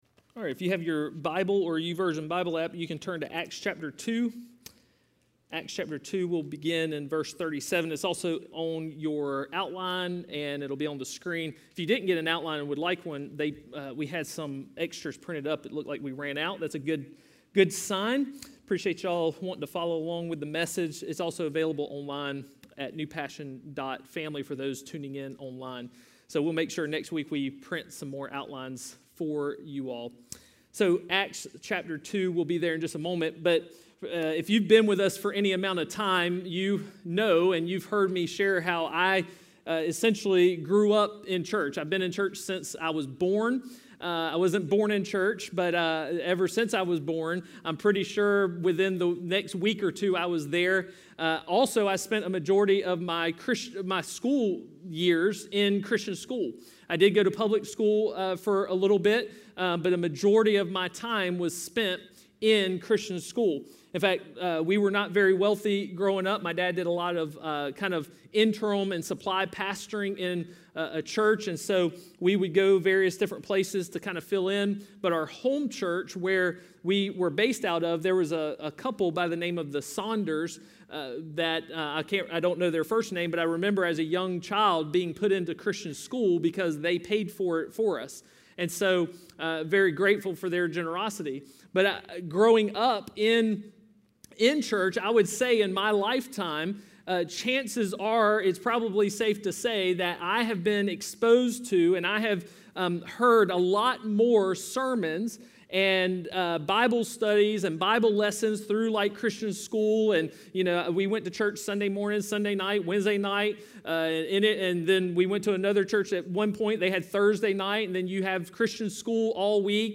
A message from the series "Disciple."